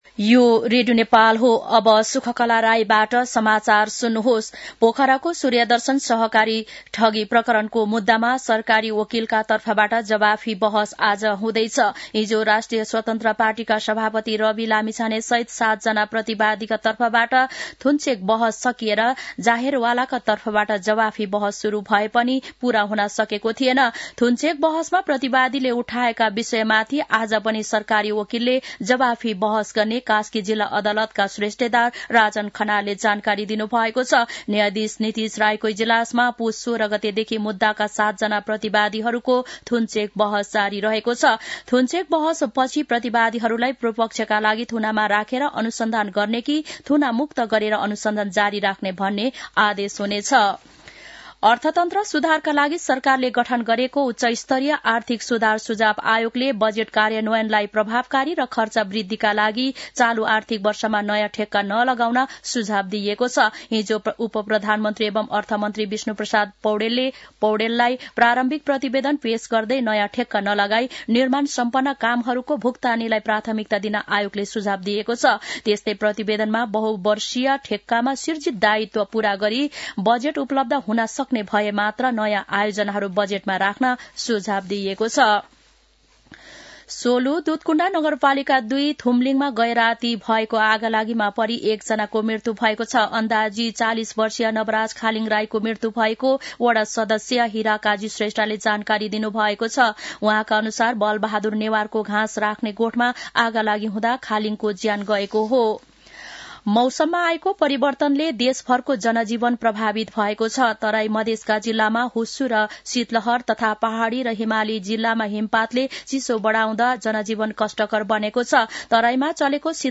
मध्यान्ह १२ बजेको नेपाली समाचार : २६ पुष , २०८१
12-am-news-1-3.mp3